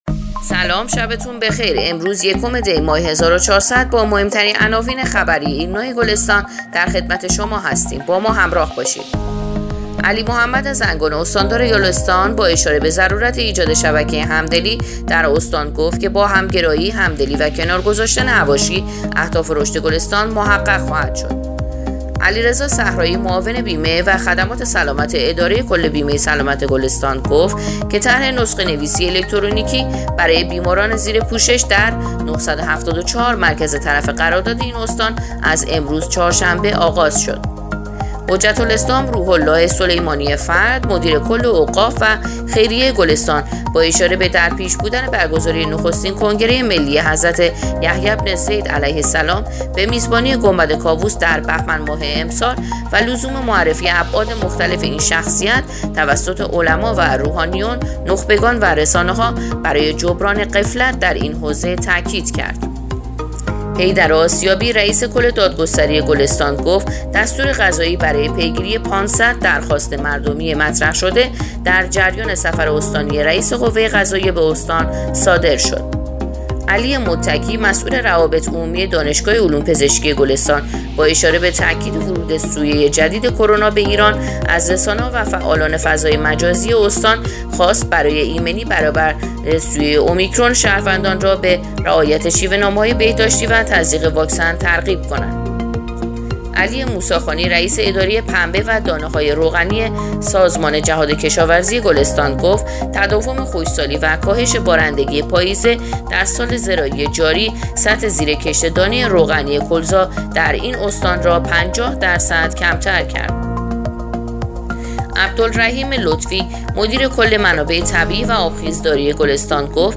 پادکست/ اخبار شبانگاهی یکم دی ماه ایرنا گلستان